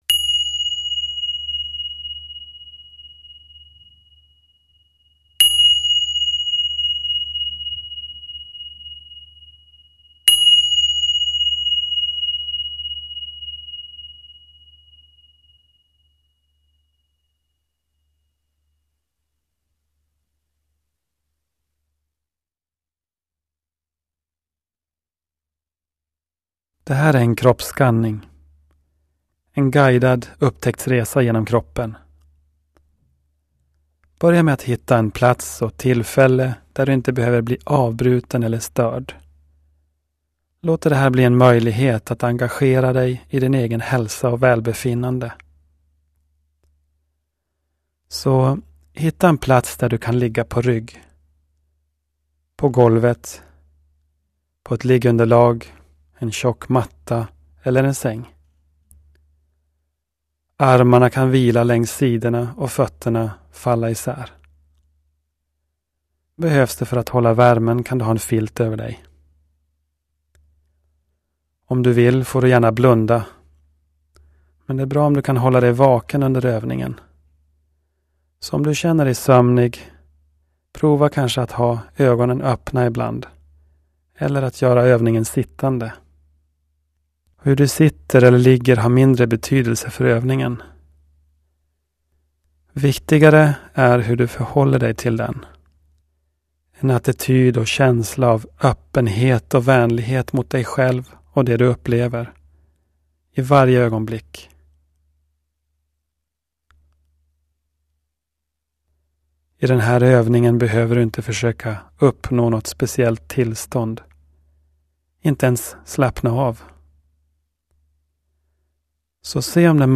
Guidade mindfulnessmeditationer i liggande, sittande och i rörelse